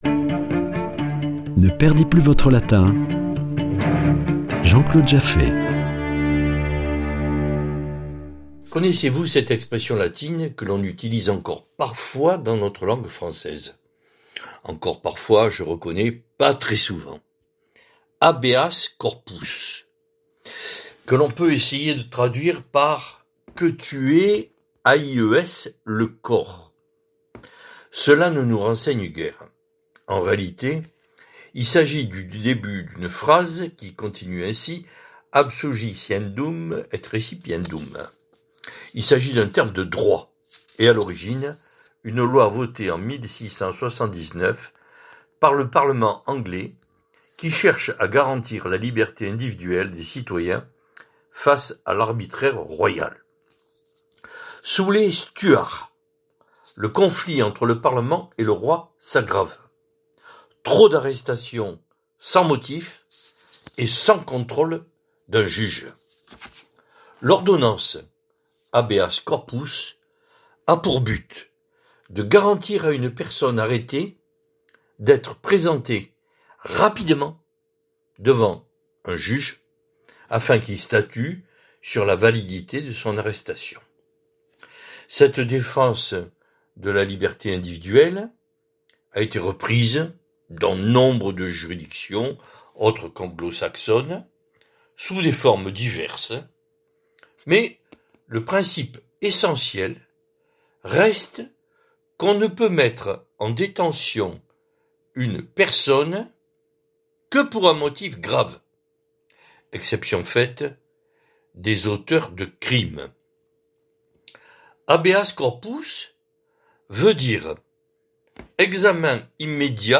Chronique Latin